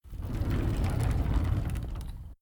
door.ogg